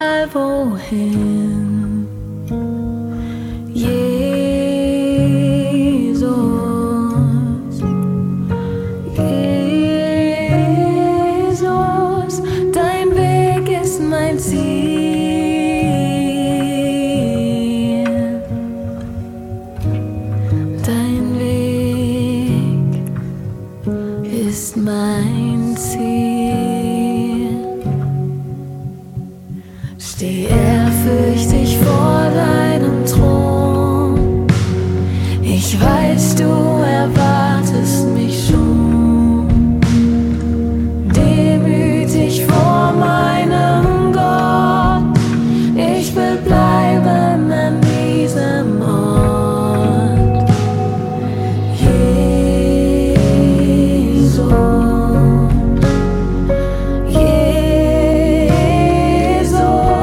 Lobpreis
Gesang